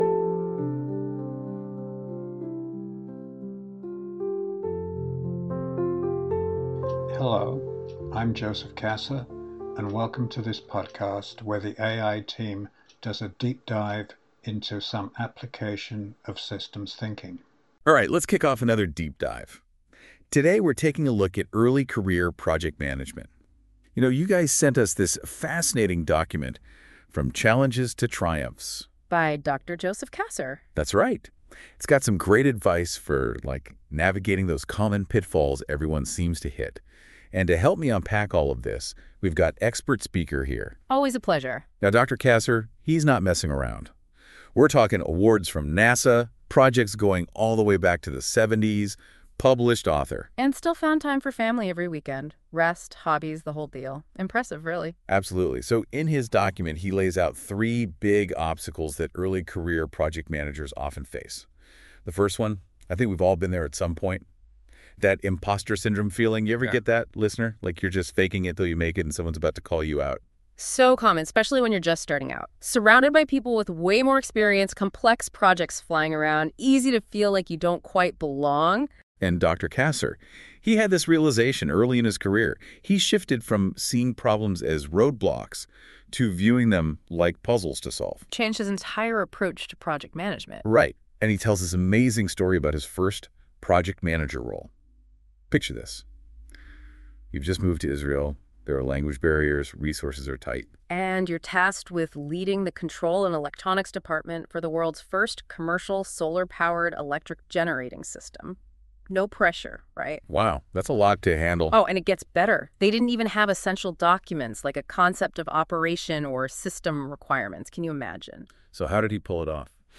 The AI team take a deep dive into a webinar